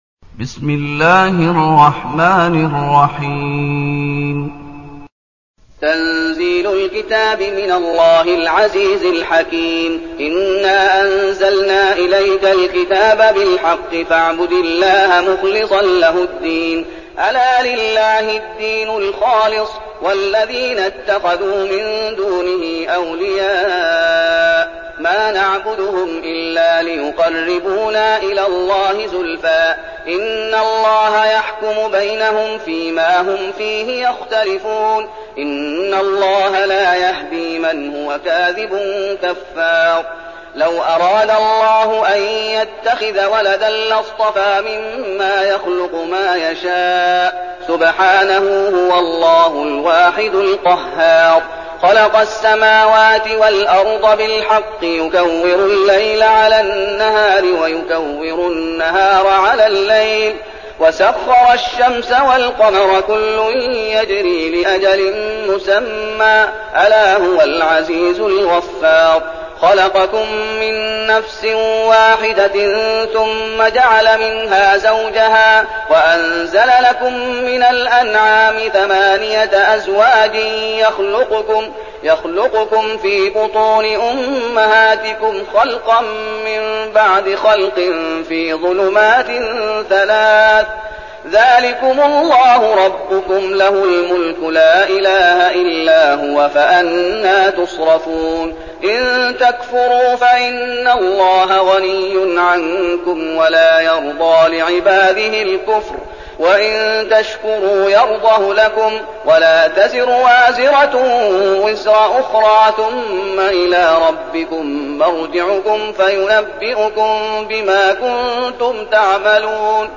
تلاوة سورة الزمر
تاريخ النشر ١ محرم ١٤١٠ المكان: المسجد النبوي الشيخ: فضيلة الشيخ محمد أيوب فضيلة الشيخ محمد أيوب سورة الزمر